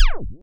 kenney_sci-fi-sounds
laserSmall_004.ogg